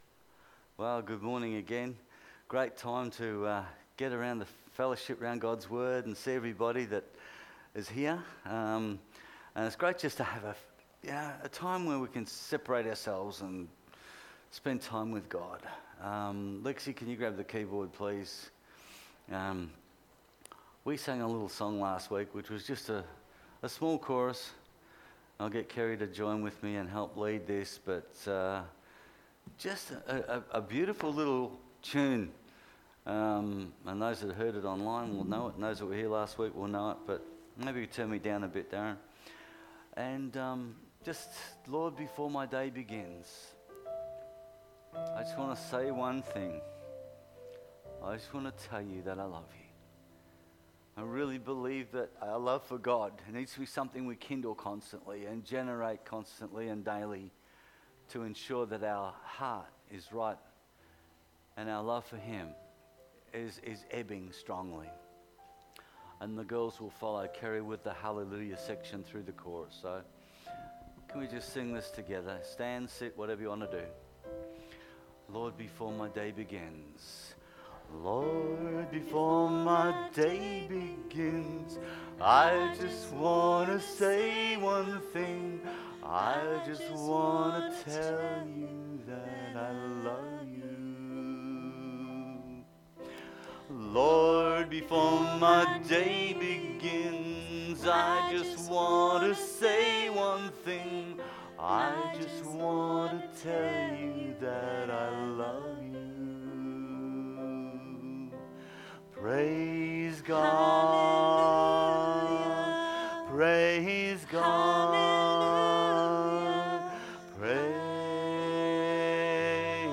Sunday Service July 5th 2020